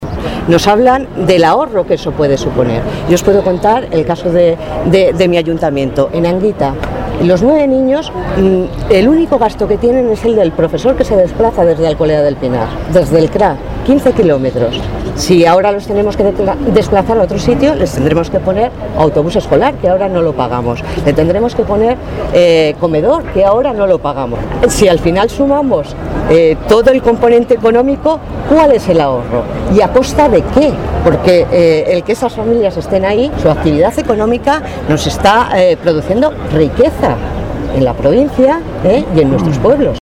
La concejala del Ayuntamiento de Anguita, Yolanda Lozano, ha expresado la preocupación de alcaldes y vecinos ante la falta de información y la amenaza de cierre de escuelas, que afectará a numerosos pueblos de la provincia.
Cortes de audio de la rueda de prensa